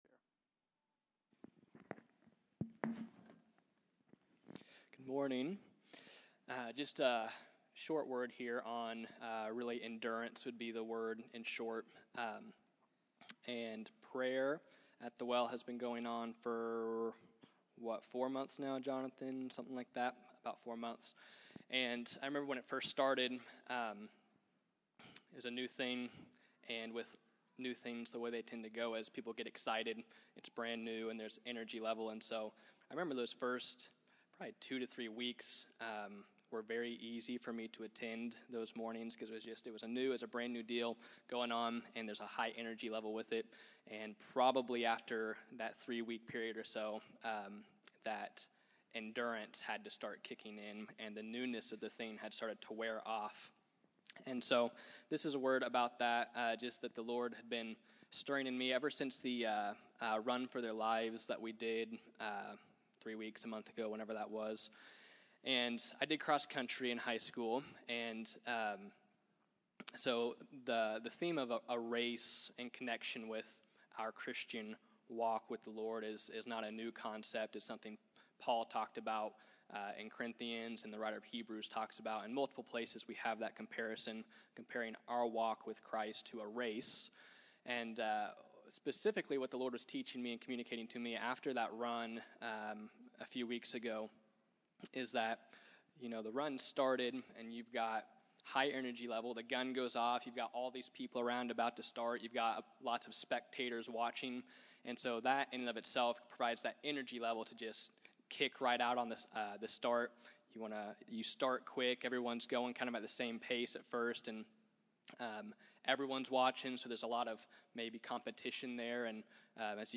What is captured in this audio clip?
Category: Encouragements